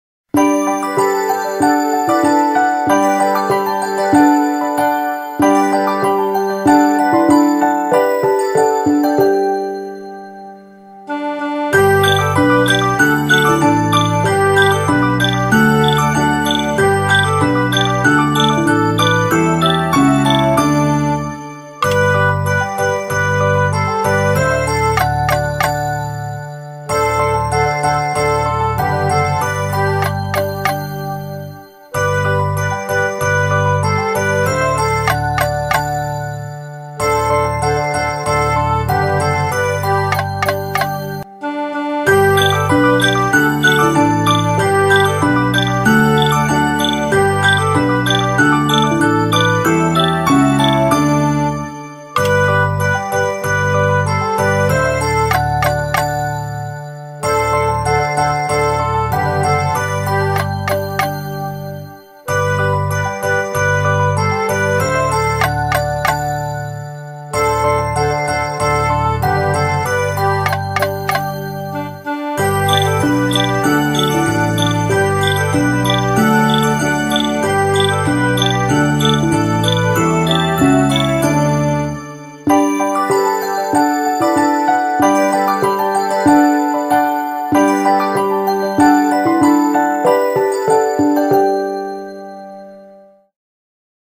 Feu un comentari / Per / / Nascuts 2011 / P5A, P5B